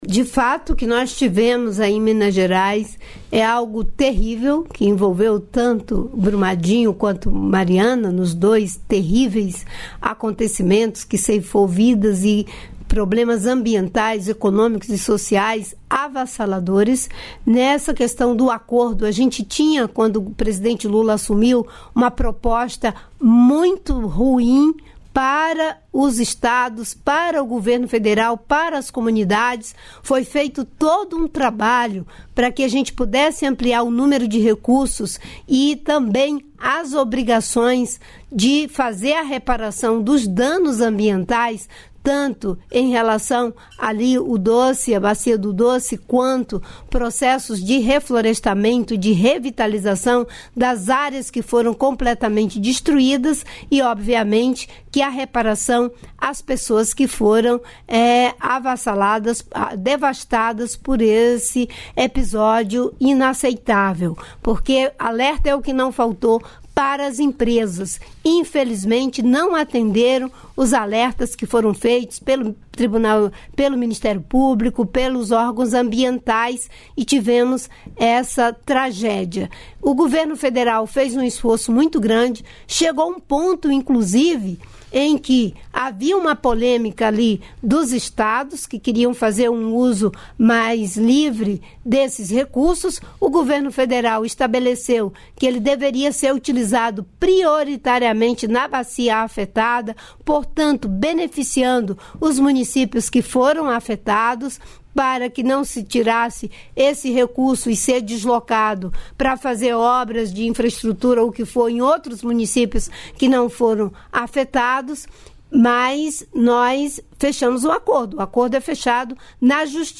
Trecho da participação ministra do Meio Ambiente e Mudança do Clima, Marina Silva, no programa "Bom Dia, Ministra" desta segunda-feira (05), nos estúdios da EBC em Brasília (DF).